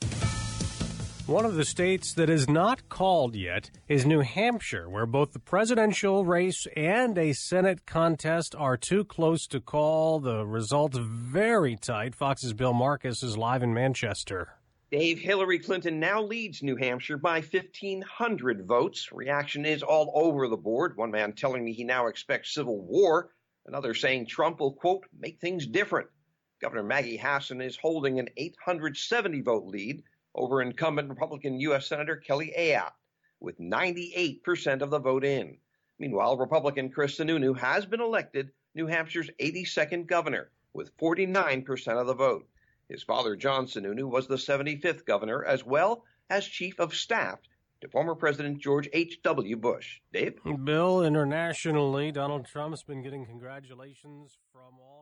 (MANCHESTER, NH) NOV 9, 10AM, LIVE: